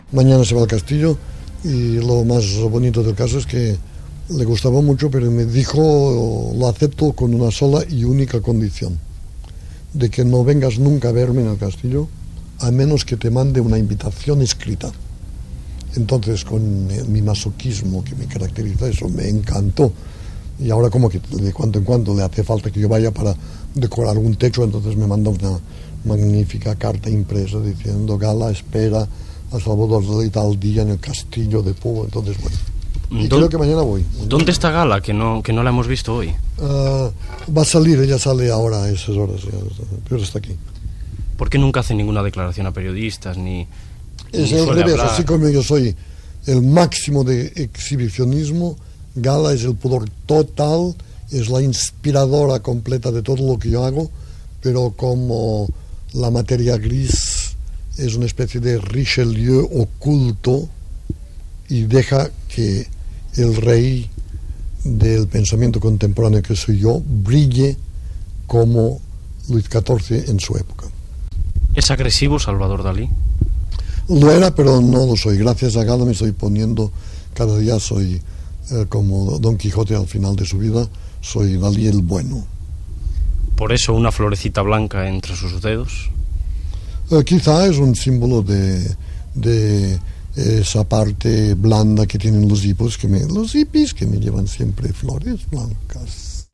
Entrevista al pintor Salvador Dalí sobre el regal que ha fet a Gala (Gala Éluard Dalí ): el castell de Púbol